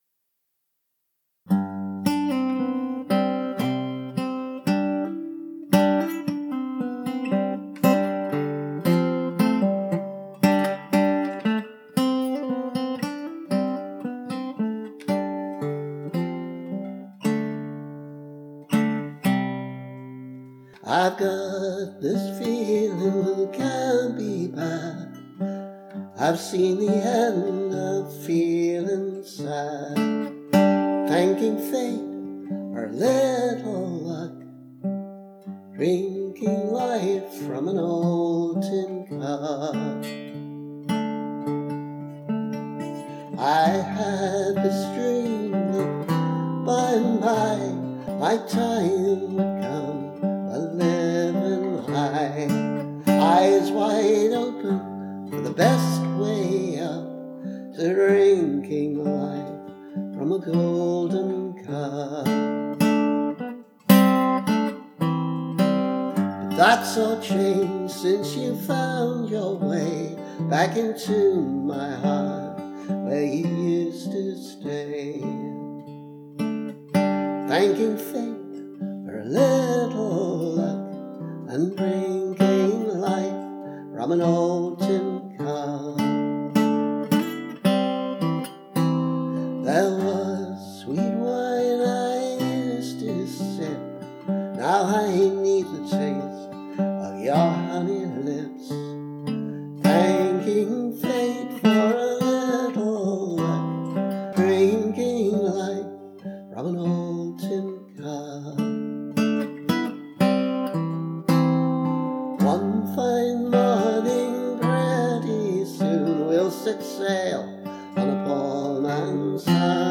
A curiously old-fashioned song.